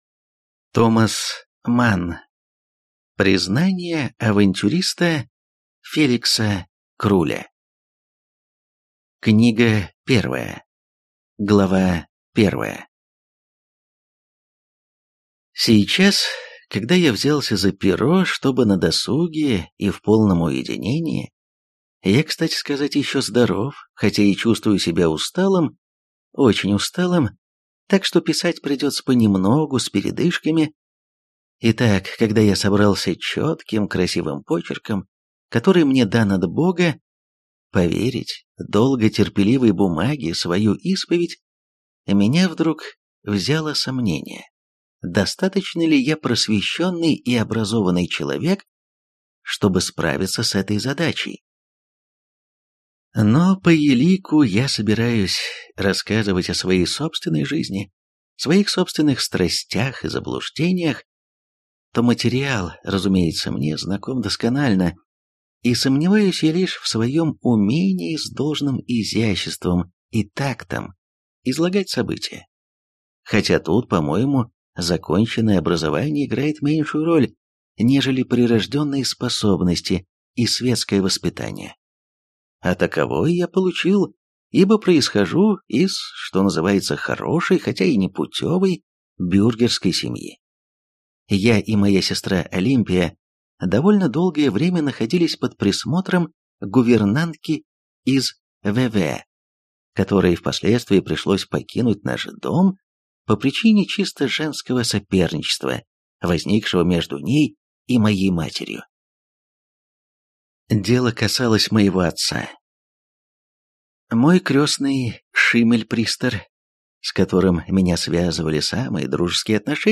Аудиокнига Признания авантюриста Феликса Круля | Библиотека аудиокниг